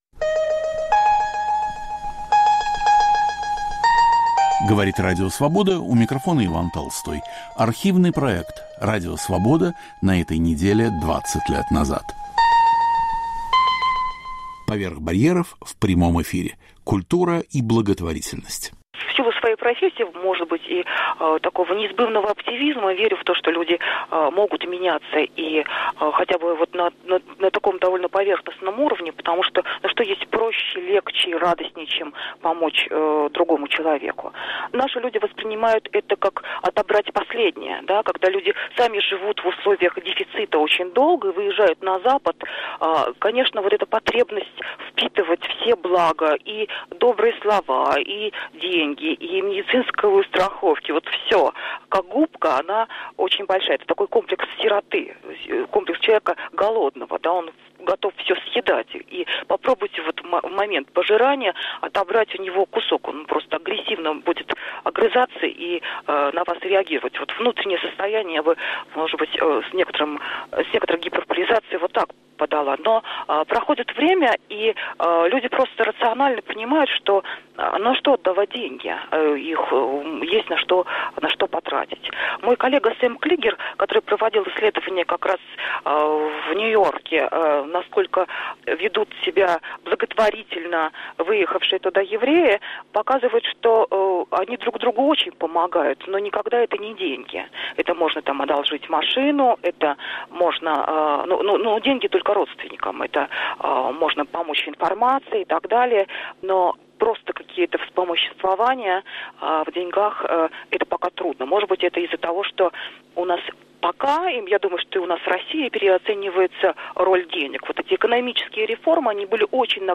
"Поверх барьеров" в прямом эфире. Культура и благотворительность